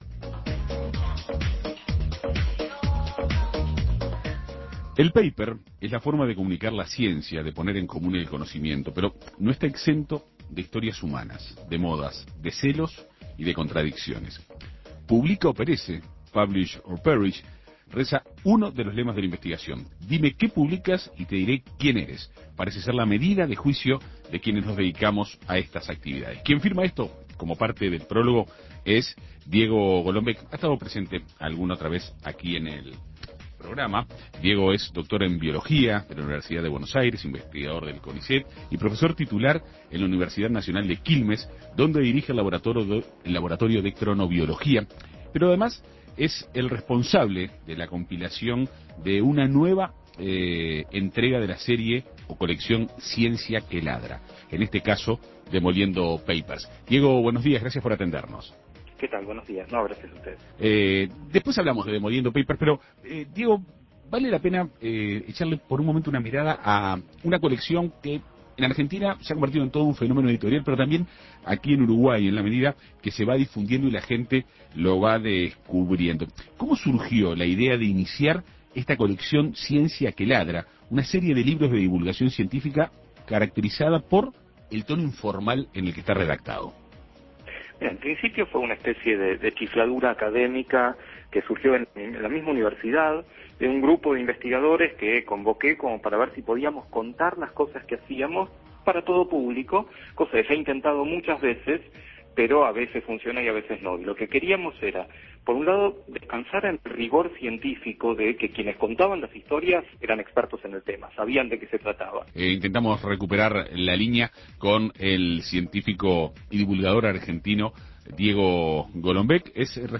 Diego Golombek, compilador de una nueva entrega de la serie "Ciencia que ladra", denominada "Demoliendo papers", dialogó con En Perspectiva Segunda Mañana.